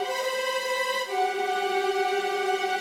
Index of /musicradar/gangster-sting-samples/85bpm Loops
GS_Viols_85-CG.wav